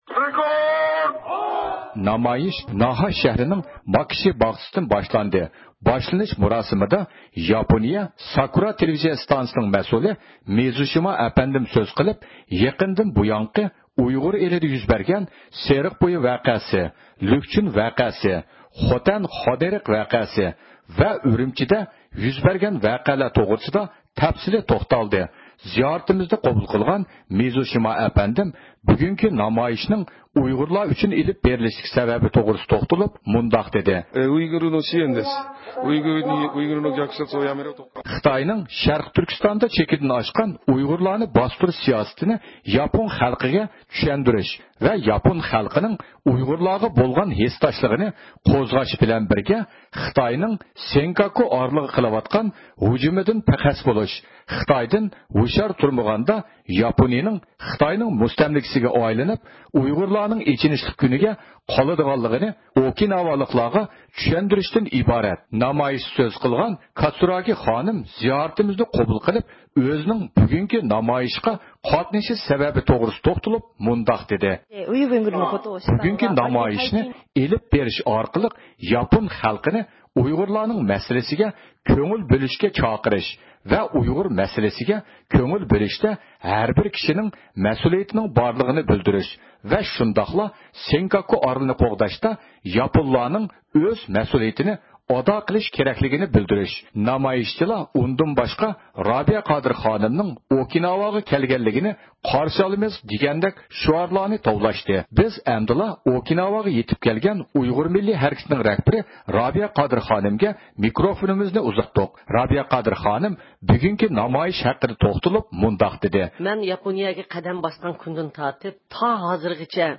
بىز ئەمدىلا ئوكىناۋاغا يېتىپ كەلگەن ئۇيغۇر مىللىي ھەرىكىتىنىڭ رەھبىرى رابىيە قادىر خانىمغا مىكروفونىمىزنى ئۇزاتتۇق.